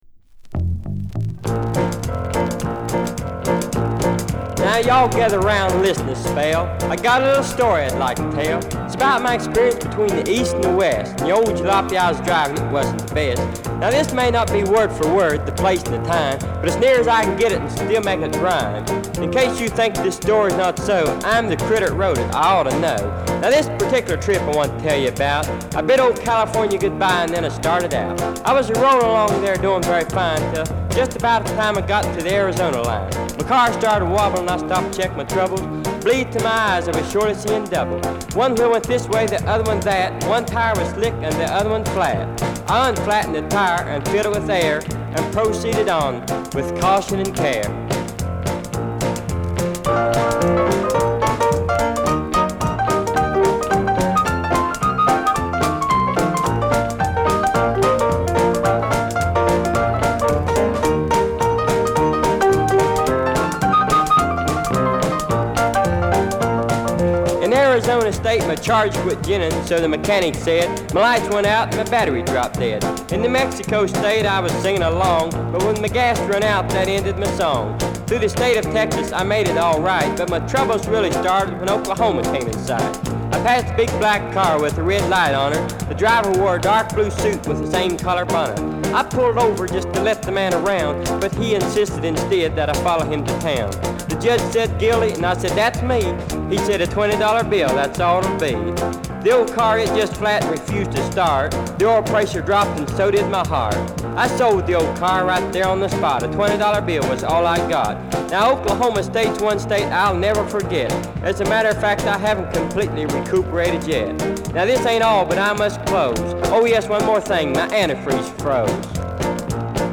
Route 66テーマの軽快なカントリー・ロッカー。